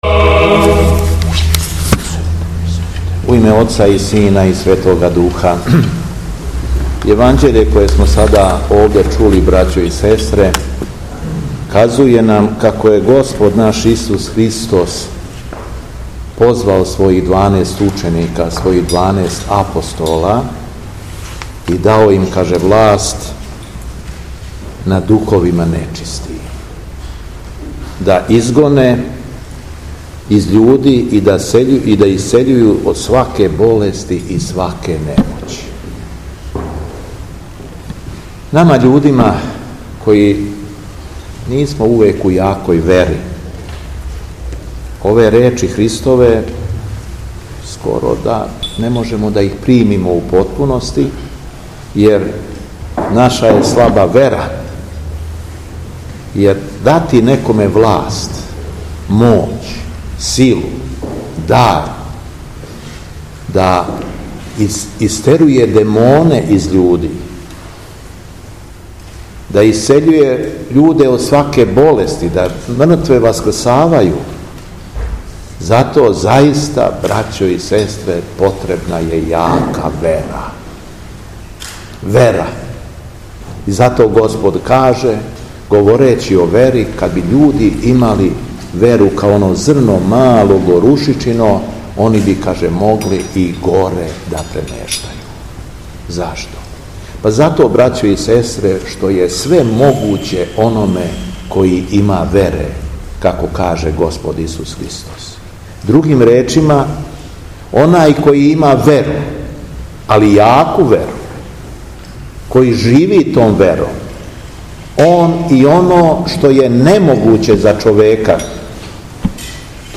Беседа Његовог Преосвештенства Епископа шумадијског Г. Јована
Након прочитаног Јеванђељског зачала, Владика је произнео беседу поучавајући своју верну паству. На првом месту, Владика је беседио о смислу и значају вере, говорећи да је вера покретач свега и снага човека.